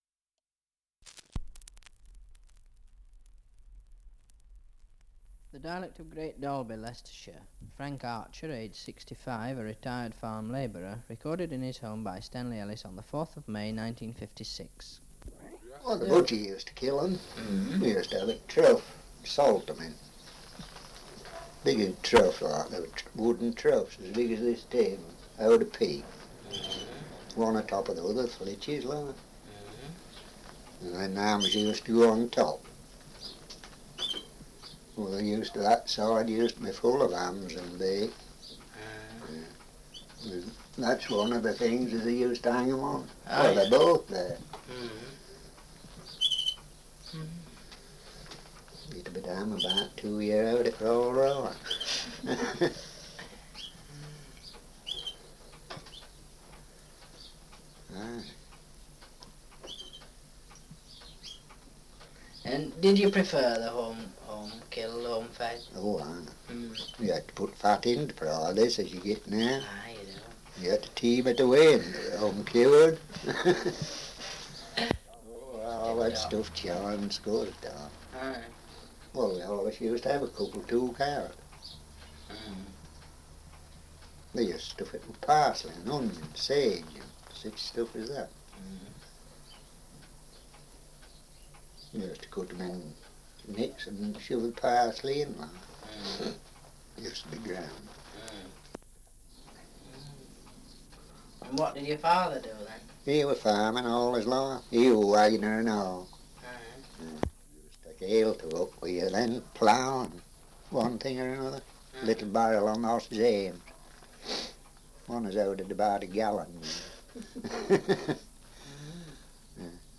Survey of English Dialects recording in Great Dalby, Leicestershire
78 r.p.m., cellulose nitrate on aluminium